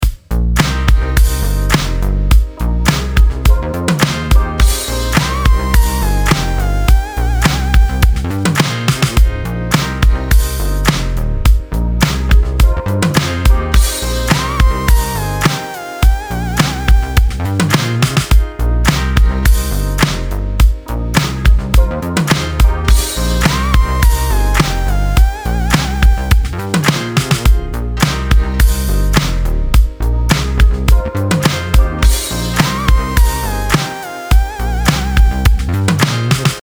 EDIT: bon voilà la seule instru "west coast" que j'ai faite si je me souviens...
...la basse, le p'tit lead... 8)